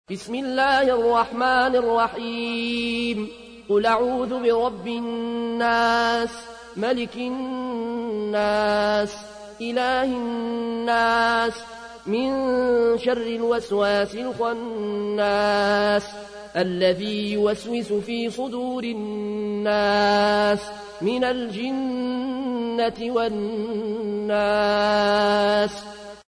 تحميل : 114. سورة الناس / القارئ العيون الكوشي / القرآن الكريم / موقع يا حسين